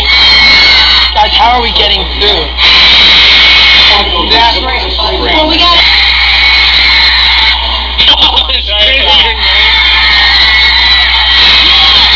How Are We Gonna Get Out There? This is from when they are getting ready for their Paramus Park Mall performance and they are like, "OHMIGOD?!" because there are so many people there.